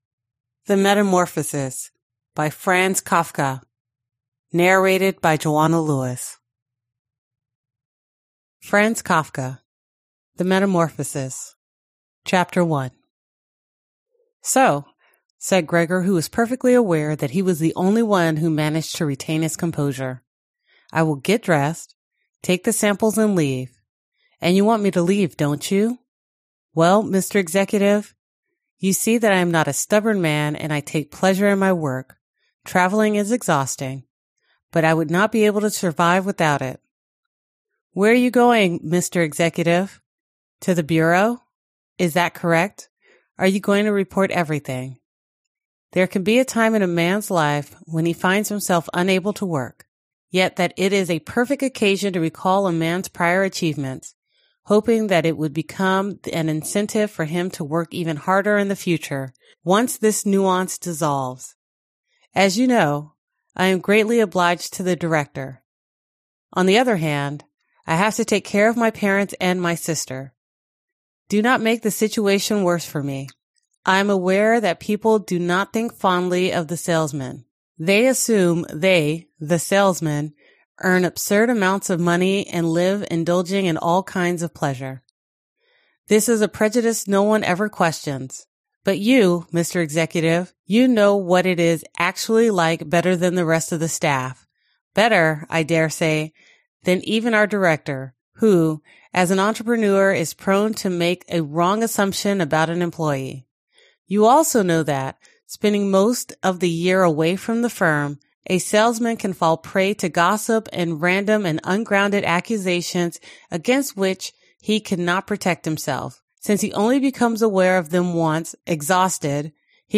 Аудиокнига The Metamorphosis | Библиотека аудиокниг